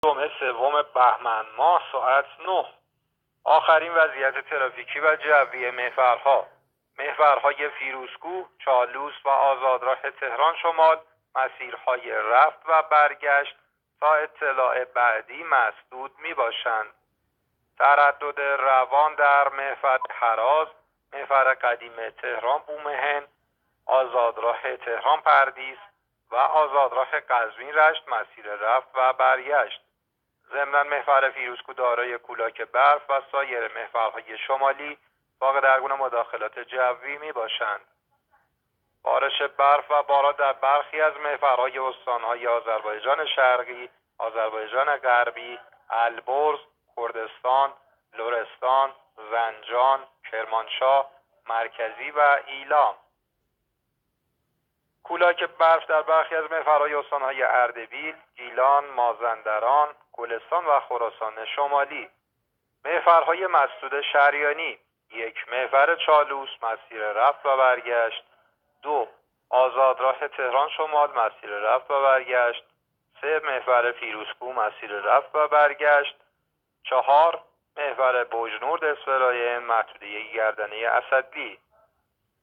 گزارش رادیو اینترنتی از آخرین وضعیت ترافیکی جاده‌ها ساعت ۹ سوم بهمن؛